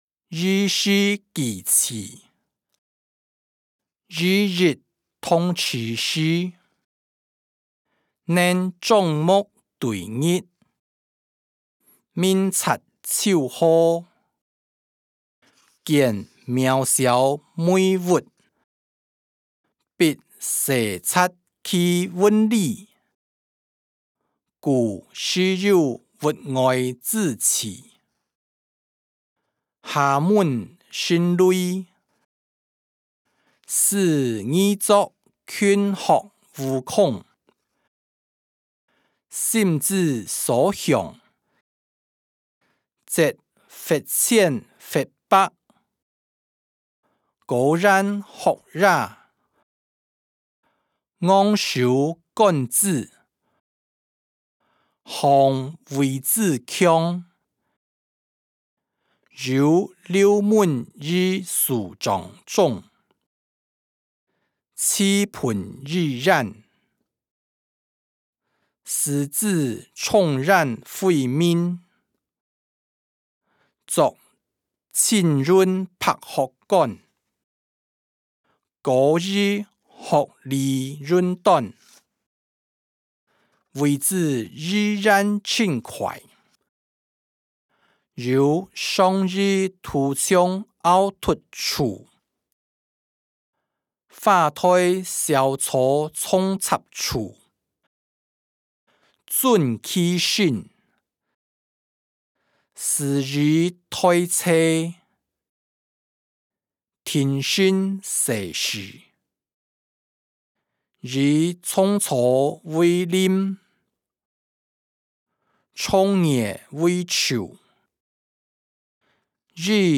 歷代散文-兒時記趣音檔(海陸腔)